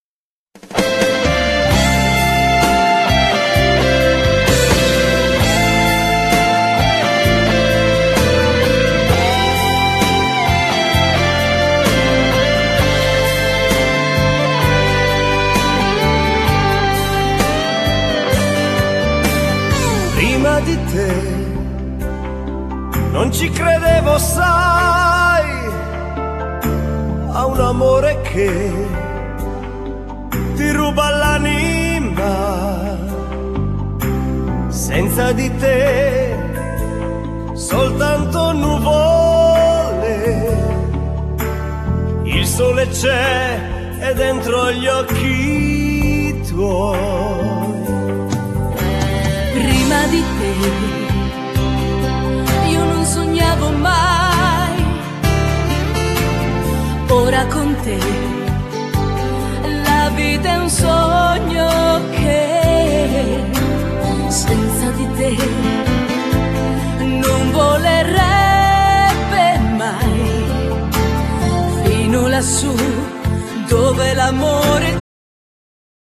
Genere : Liscio Folk